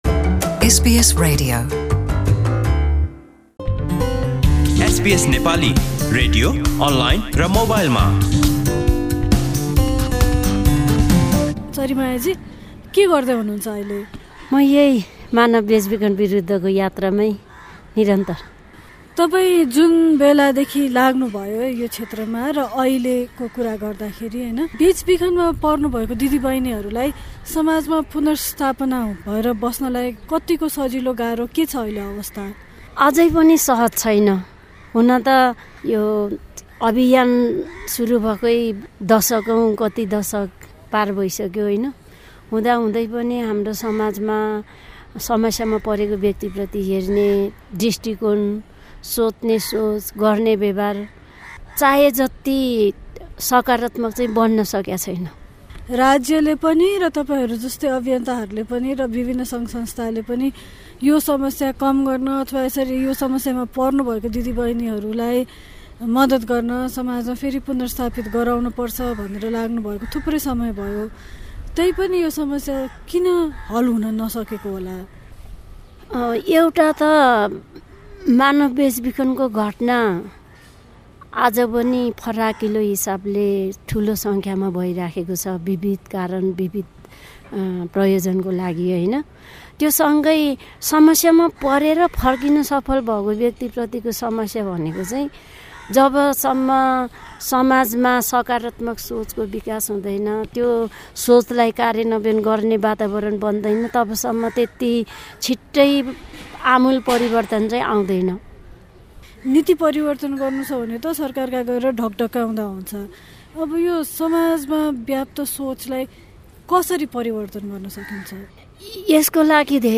हामीले उनको सङ्घर्ष र बेचबिखनमा परेका महिलाहरूलाई समाजमा पुनर्स्थापना हुन कत्तिको सहज छ भन्ने विषयमा कुराकानी गरेका छौँ।